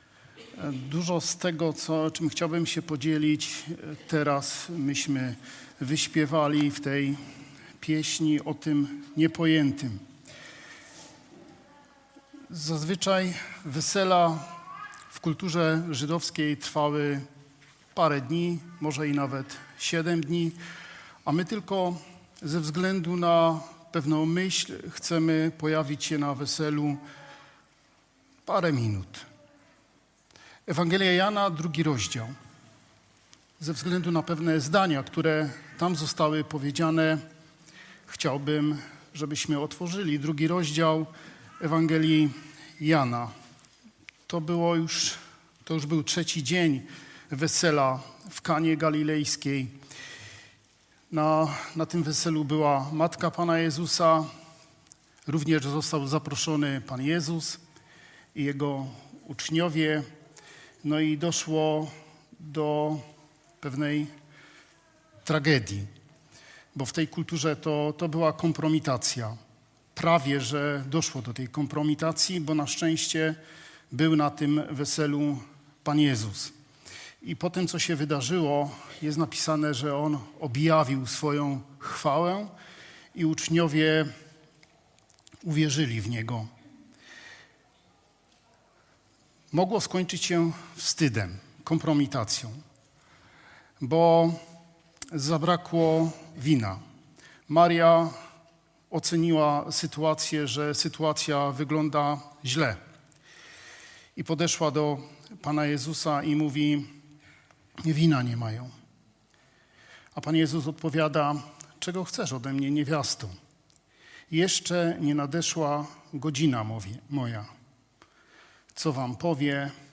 KAZANIE JAN 2,1-12